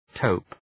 Προφορά
{təʋp}